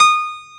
AcousticPiano(5)_D#6_22k.wav